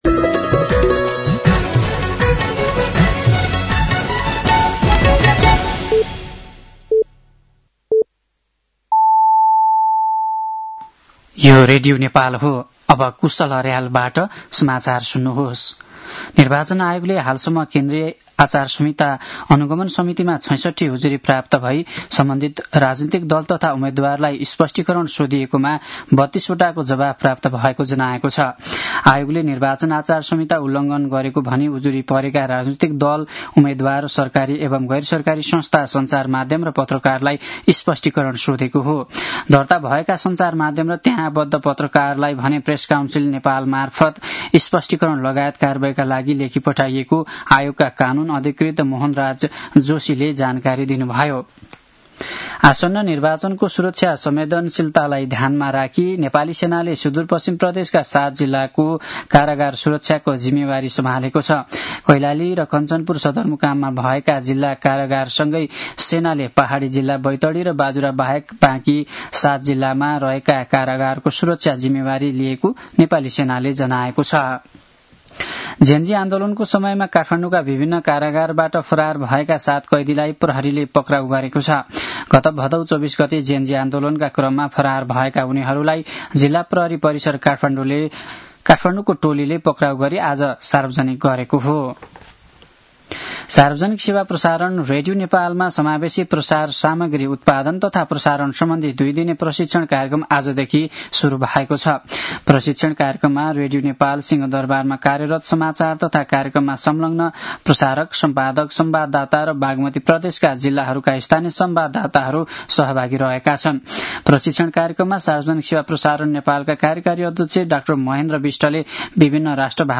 साँझ ५ बजेको नेपाली समाचार : ४ फागुन , २०८२
5.-pm-nepali-news-1-9.mp3